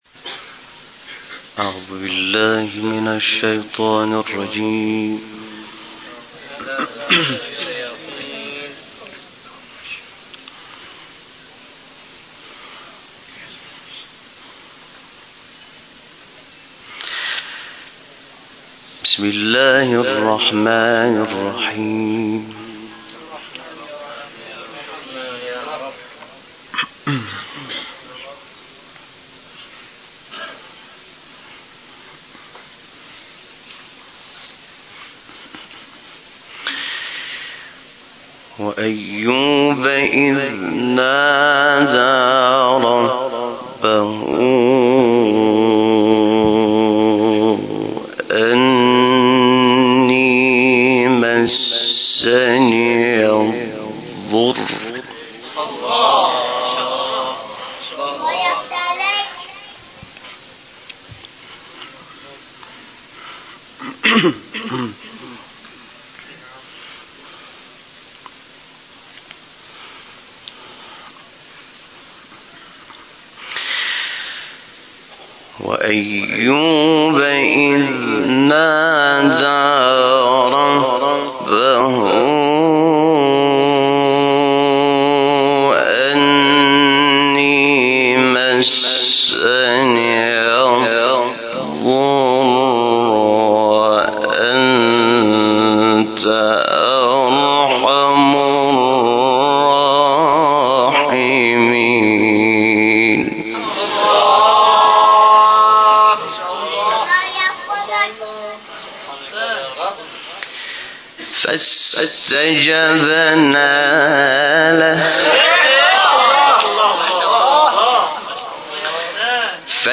گروه شبکه اجتماعی: حامد شاکرنژاد در آخرین شب مراسم حسینیه بنی الزهراء به تلاوت آیاتی از سوره‌های مبارکه انبیاء و حاقه پرداخته است.
تلاوت شاکرنژاد در شب دهم